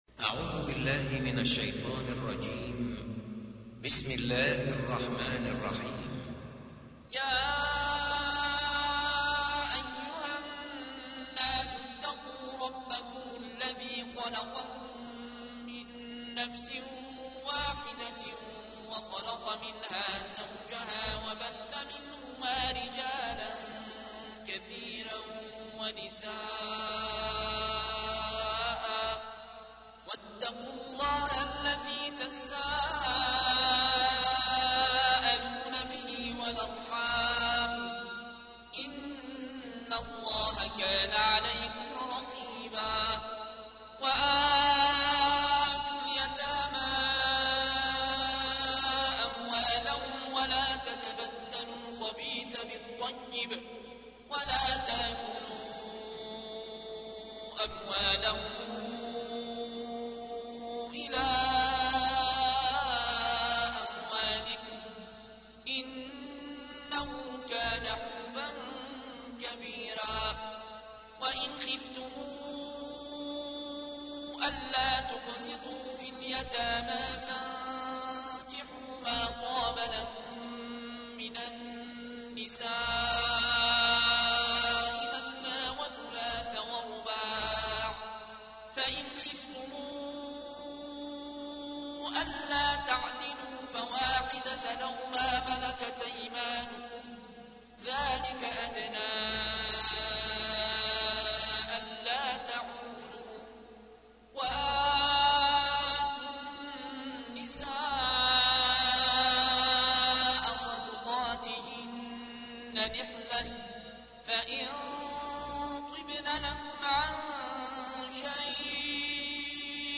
تحميل : 4. سورة النساء / القارئ العيون الكوشي / القرآن الكريم / موقع يا حسين